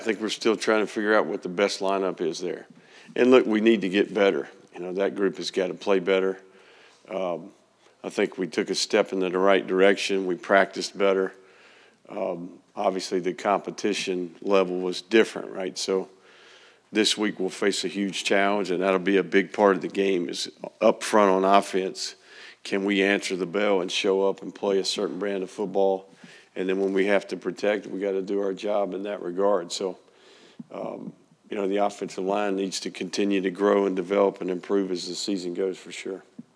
The Gators are fortunate to have two standout quarterbacks and he plans to use both in every game, Florida football coach Billy Napier said at Monday’s news conference.